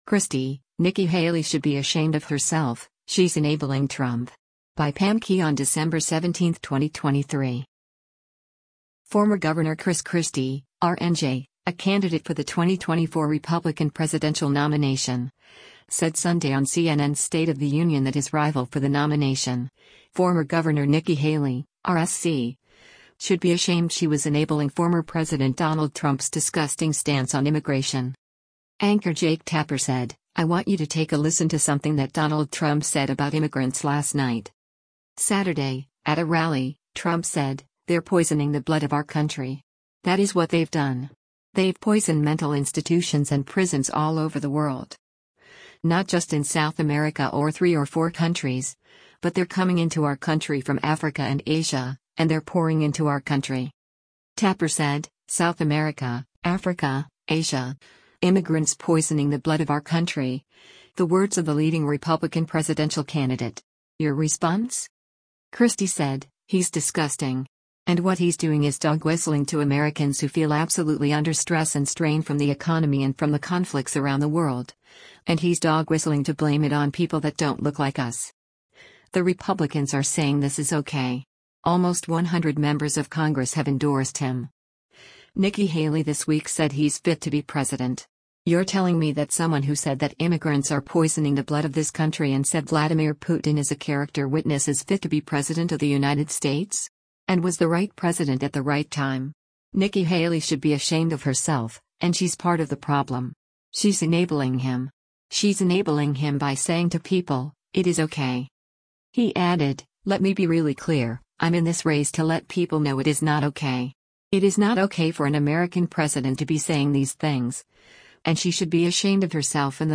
Former Gov. Chris Christie (R-NJ), a candidate for the 2024 Republican presidential nomination, said Sunday on CNN’s “State of the Union” that his rival for the nomination, former Gov. Nikki Haley (R-SC), should be “ashamed” she was enabling former President Donald Trump’s “disgusting” stance on immigration.
Anchor  Jake Tapper said, “I want you to take a listen to something that Donald Trump said about immigrants last night.”